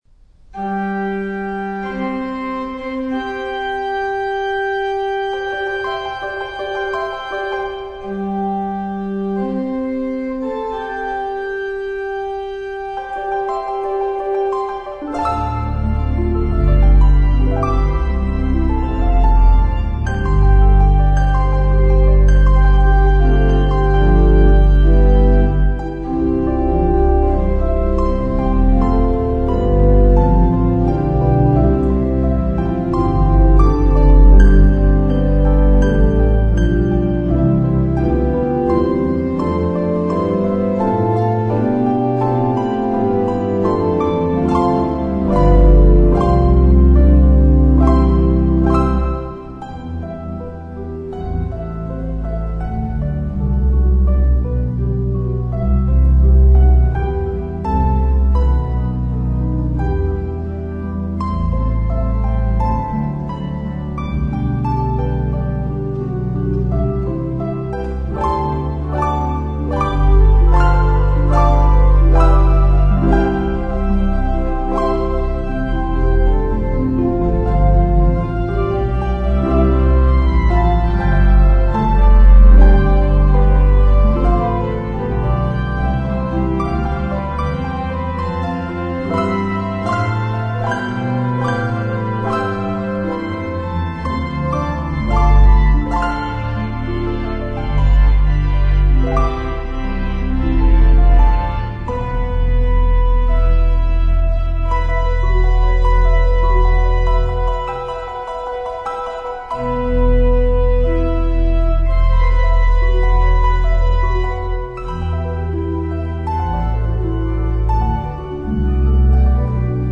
Voicing: Harp and Organ